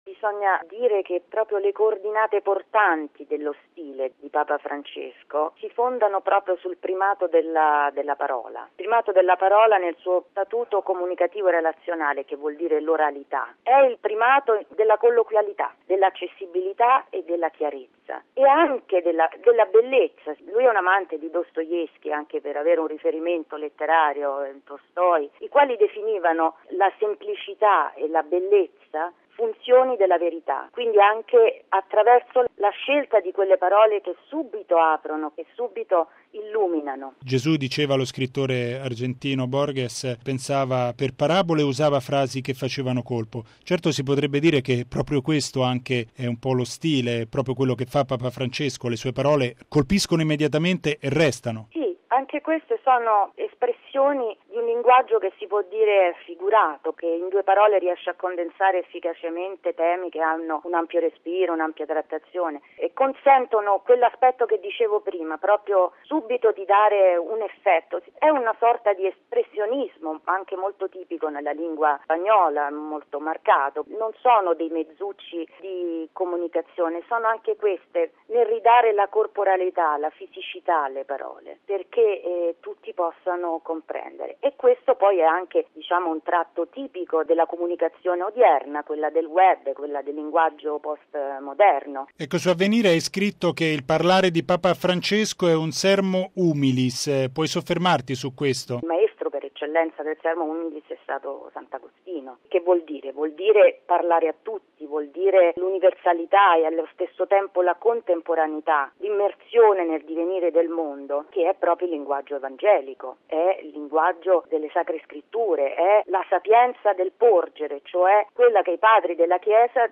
ha intervistato la giornalista